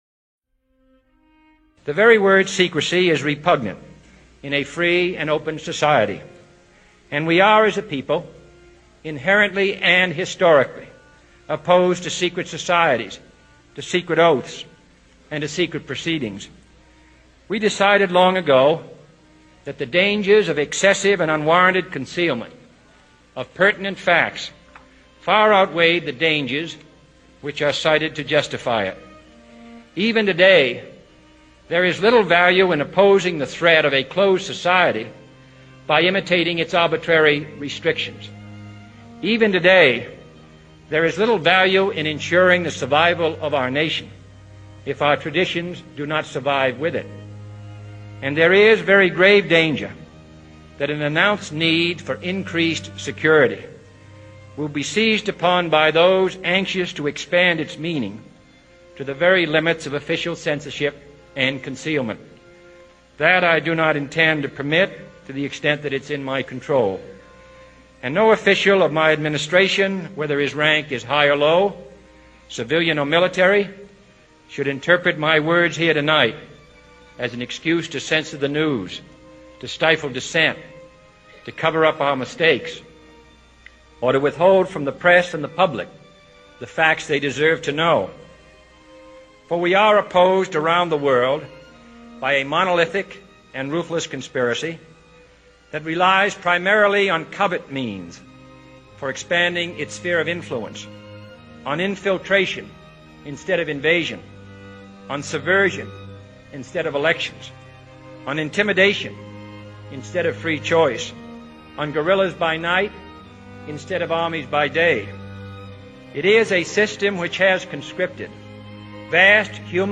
JFK - The speech that Ended his life The speech that killed JFK! הנאום שגרם למותו של ג'ון קנדי!